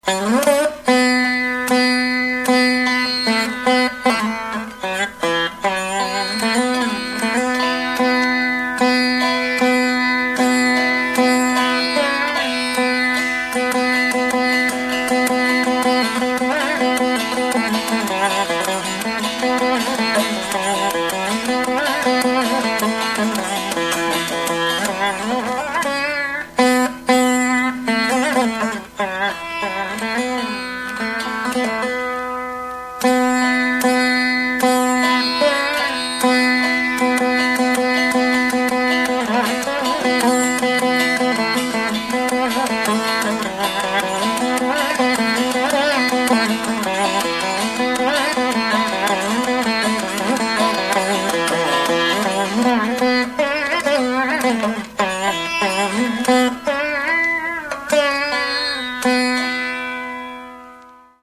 vīna